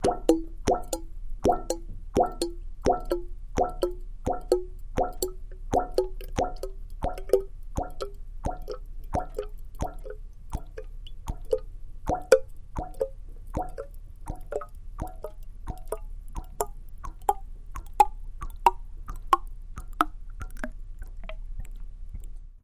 Oil Rig with Chain Movement; Oil Rig; Loud, Echoing Chain Movement, Background Motor Hum, Air Release, Close Perspective.